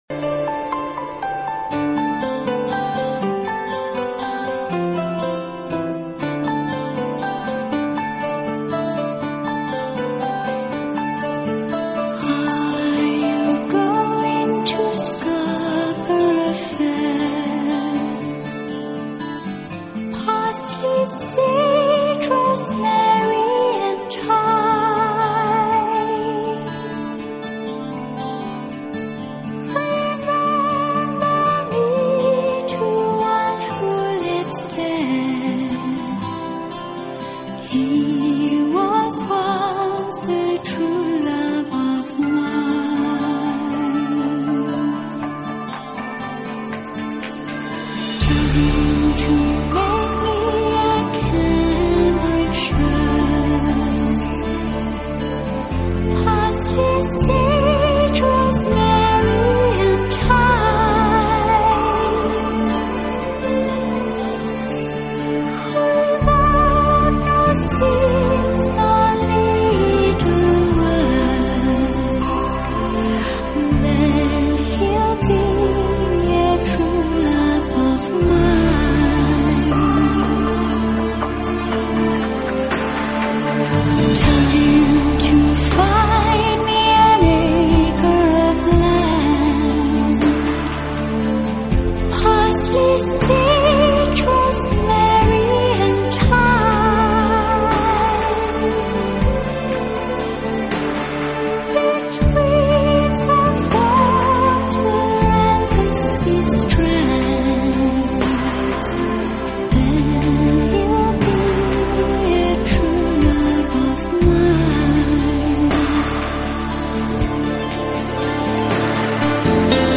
Genre: Ambient-Chill / Down-Tempo